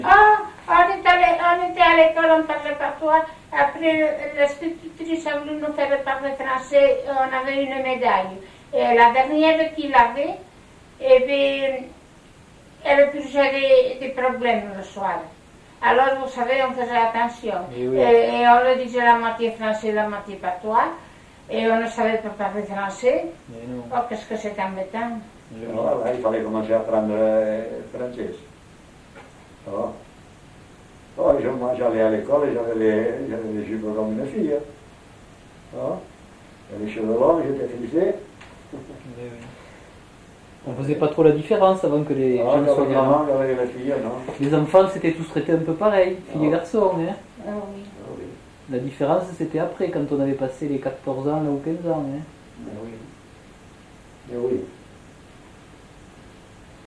Lieu : Samortein (lieu-dit)
Genre : témoignage thématique